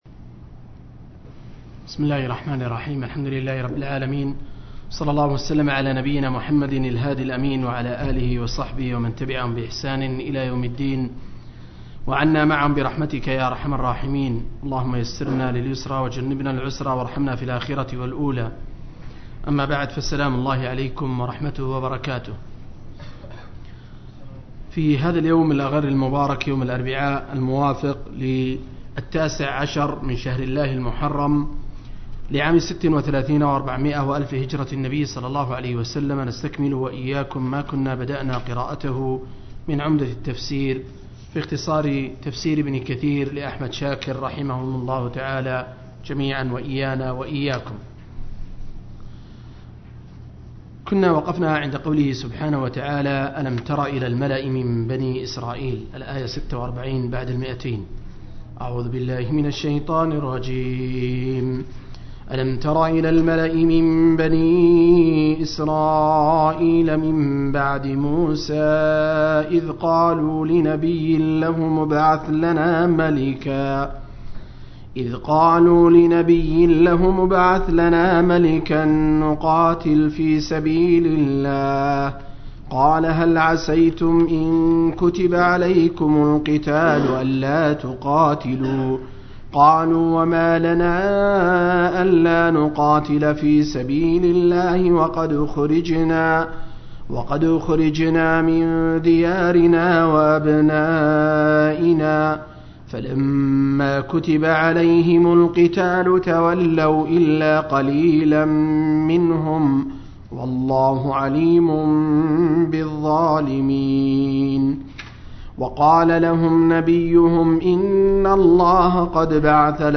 051- عمدة التفسير عن الحافظ ابن كثير – قراءة وتعليق – تفسير سورة البقرة (الآيات 252-246)